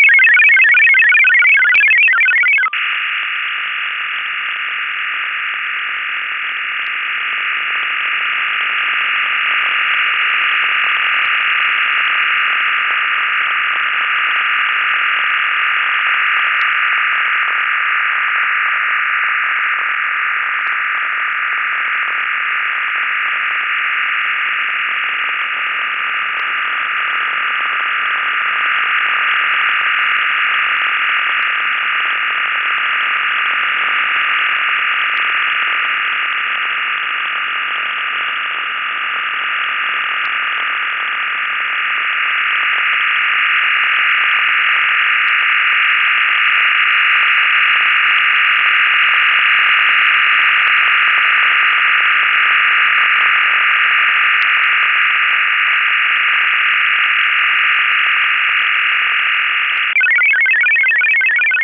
"SERDOLIK" System using OFDM waveform during the initial trials in December of 1998 "SERDOLIK" system switching from 34-tone MFSK to 25-tone OFDM "SERDOLIK" system switching from 34-tone MFSK to 34-tone OFDM
SERDOLIK_OFDM-34.WAV